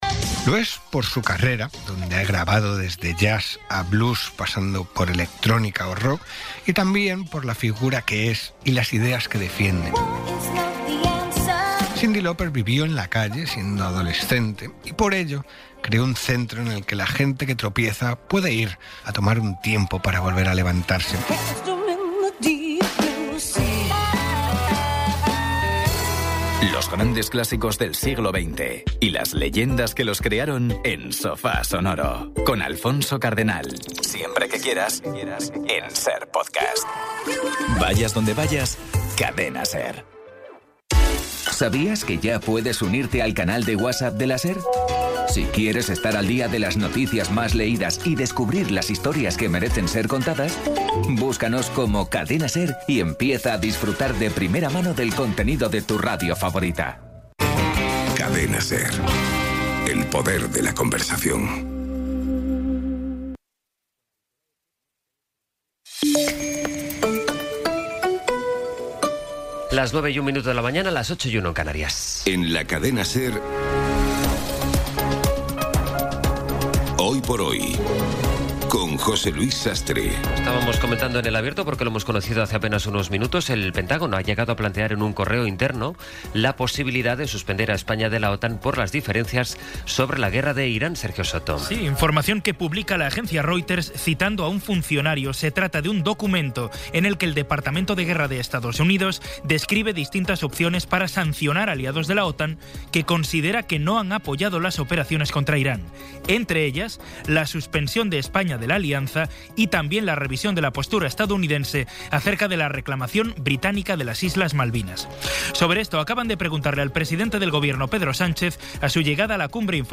Resumen informativo con las noticias más destacadas del 24 de abril de 2026 a las nueve de la mañana.